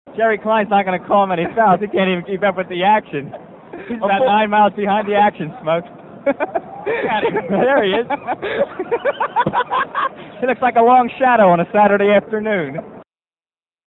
The game took place in Philadelphia's famous Palestra, at half-time of a real game between Temple and Penn State.
For that, we have the actual play-by-play audio.